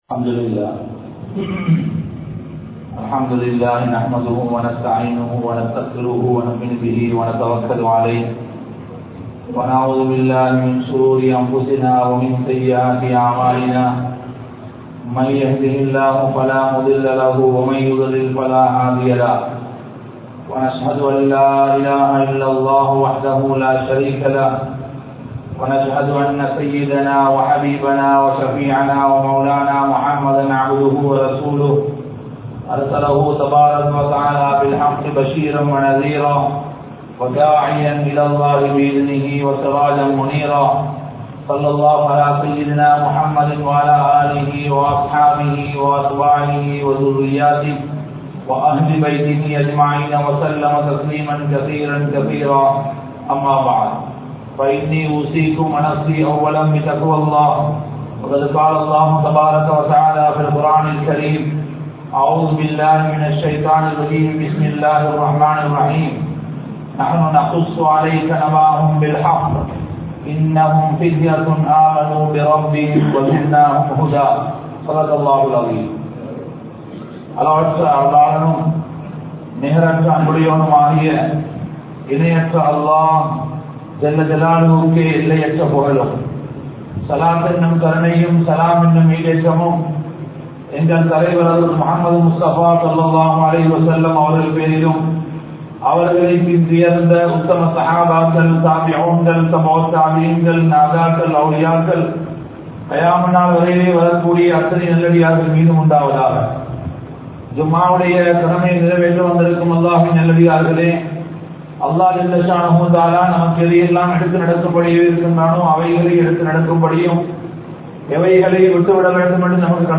Vaalifaththin Perumathi (வாலிபத்தின் பெறுமதி) | Audio Bayans | All Ceylon Muslim Youth Community | Addalaichenai
Majmaulkareeb Jumuah Masjith